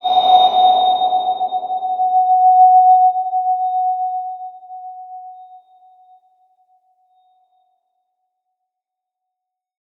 X_BasicBells-F#3-pp.wav